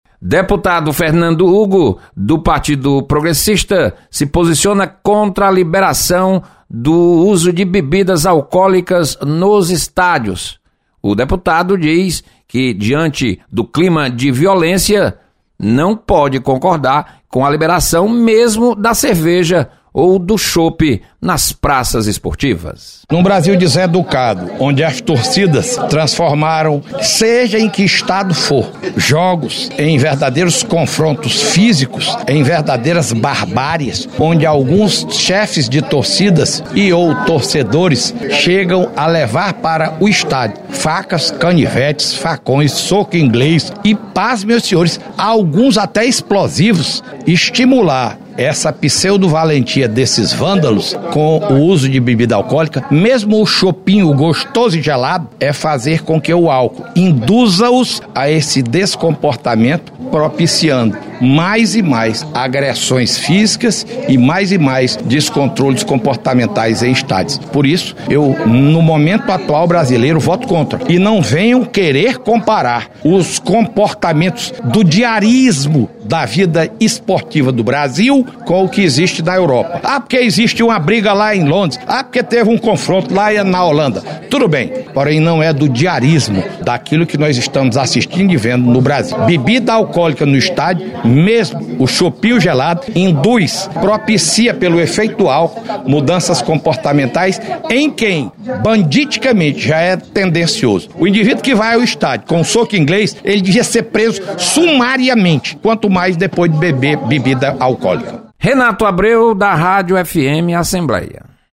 Deputado Fernando Hugo é contrário a venda de bebidas alcoólicas nos estádios de futebol. Repórter